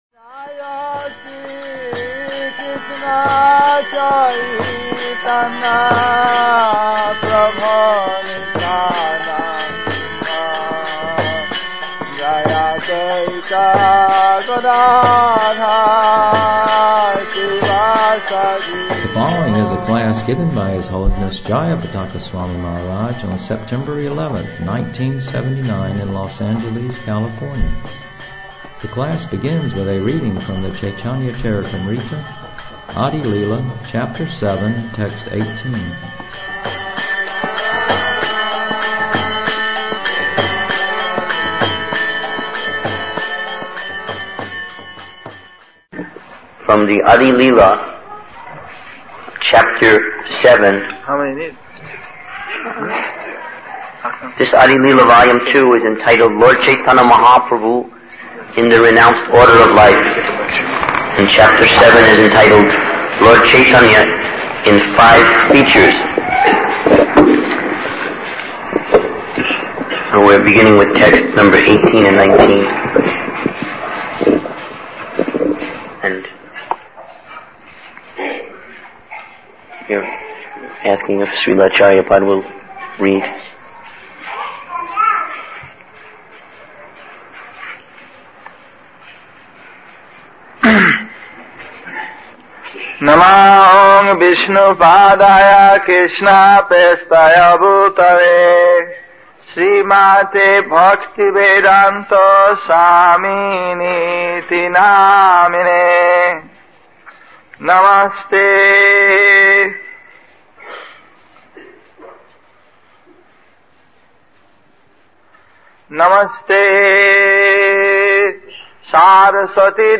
The class begins with a reading from the Caitanya Caritamrita, Adi Lila, chapter 7, text 18.